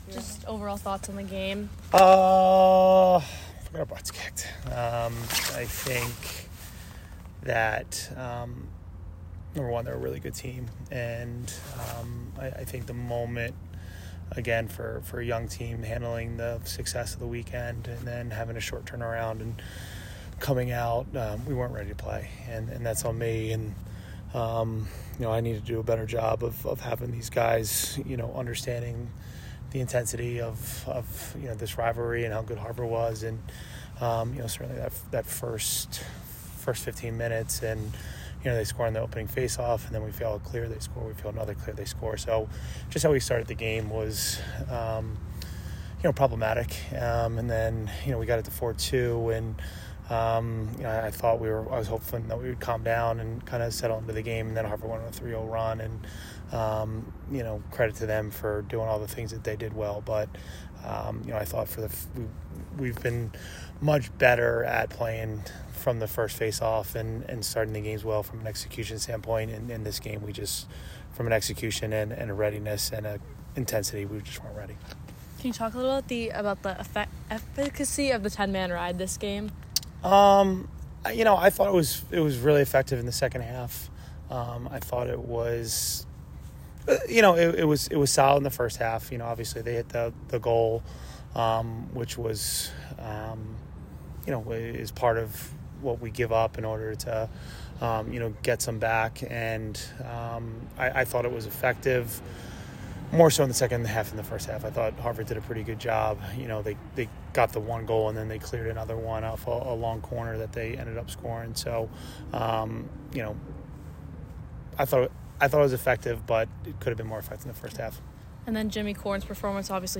Men's Lacrosse / Harvard Postgame Interview (3-25-25)